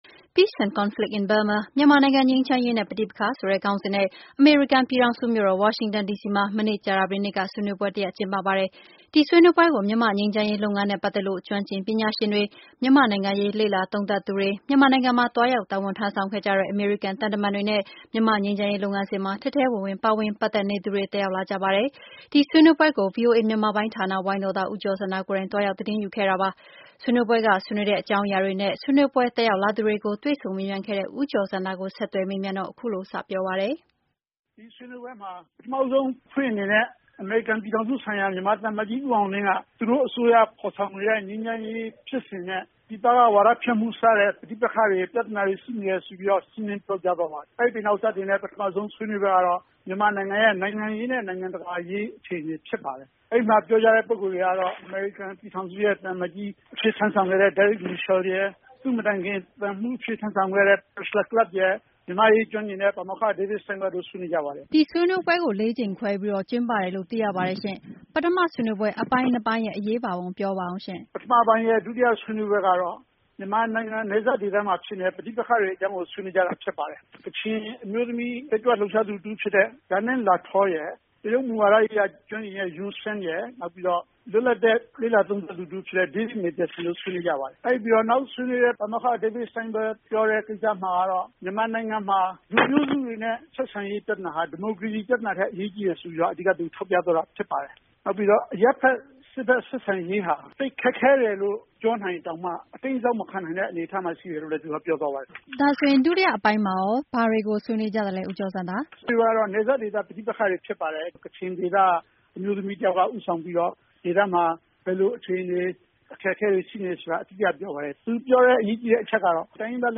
Peace and Conflict in Burma ဆွေးနွေးပွဲအတွင်း VOA မေးမြန်းစဉ်